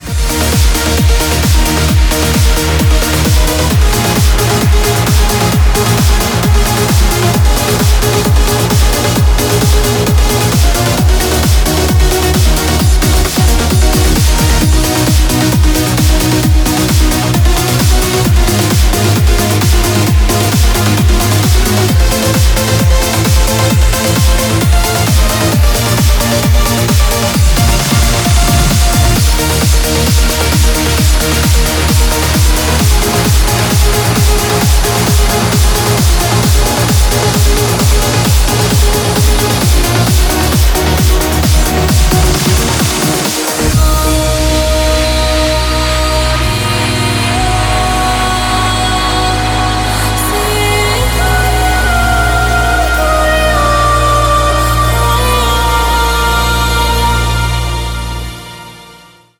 • Качество: 128, Stereo
женский вокал
dance
Electronic
EDM
club
красивый женский голос
progressive trance
vocal trance
Стиль: trance